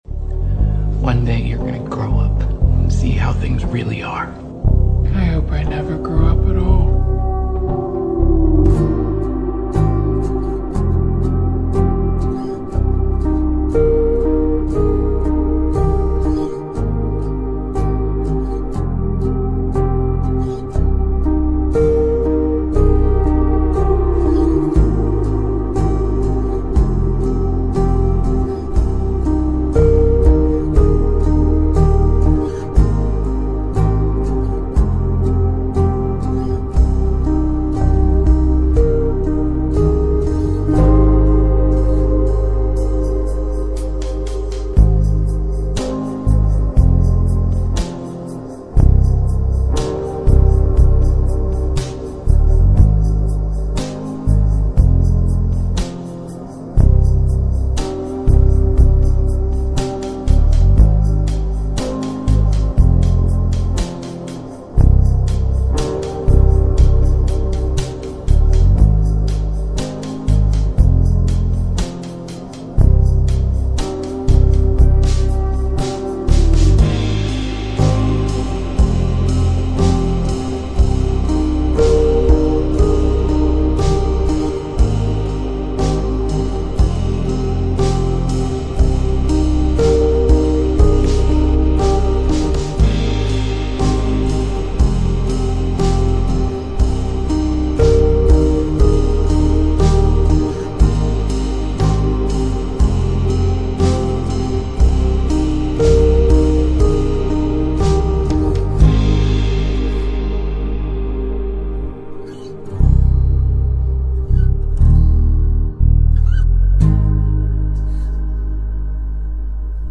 slowed (instrumental)